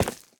Minecraft Version Minecraft Version latest Latest Release | Latest Snapshot latest / assets / minecraft / sounds / block / tuff_bricks / step5.ogg Compare With Compare With Latest Release | Latest Snapshot
step5.ogg